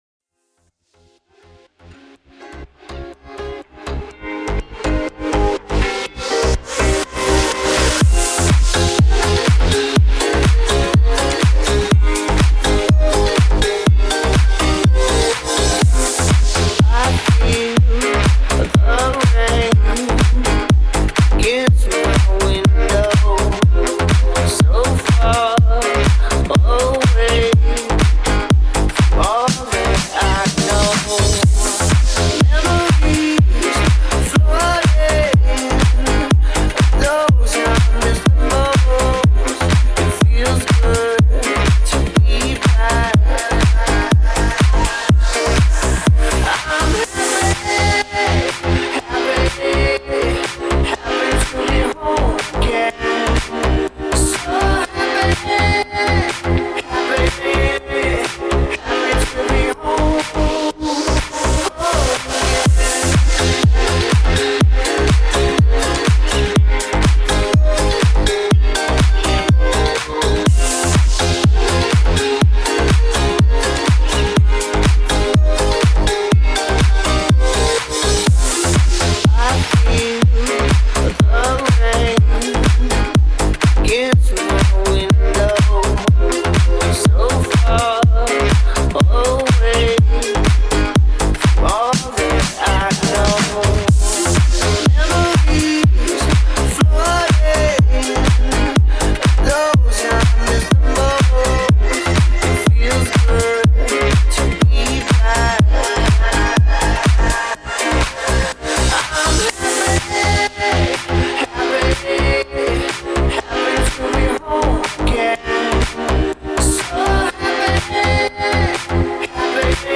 Очень позитивно и танцевально!
Сочный микс, приятный и мелодичный.